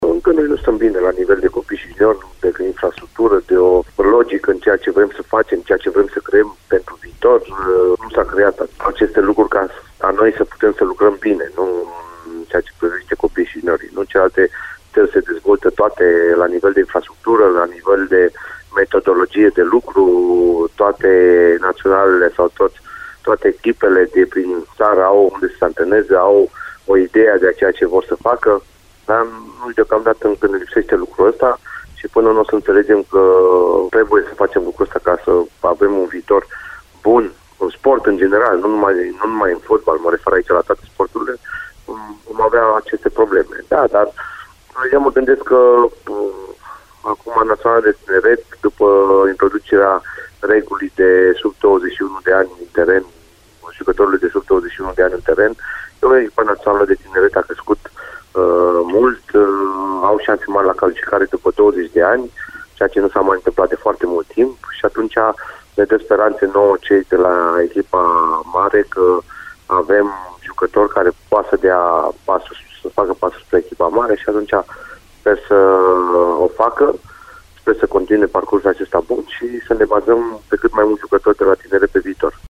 Antrenorul care luna viitoare va împlini 42 de ani pregătește meciurile ce vor încheia anul 2017, amicalele cu Turcia și Olanda, înaintea cărora a stat de vorbă cu Radio Timișoara despre planurile și cariera sa.
Într-un interviu